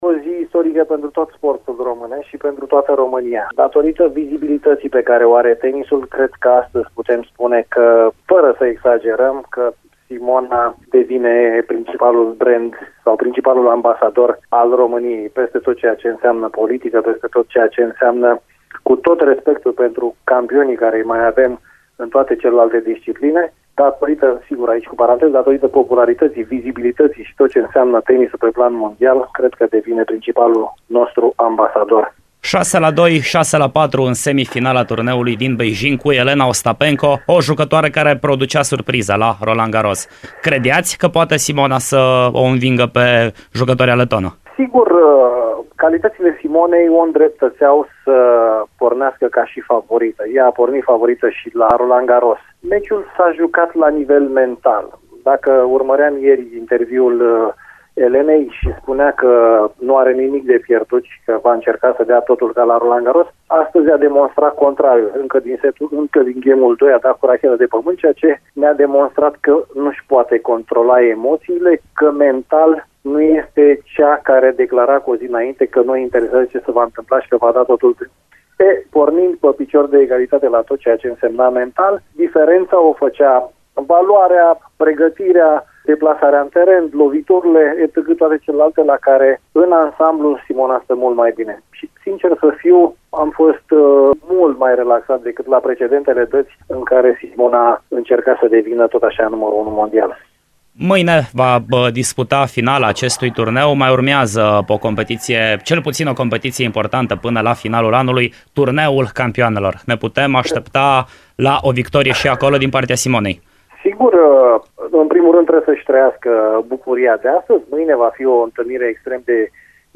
în direct la Radio Timișoara
interviul integral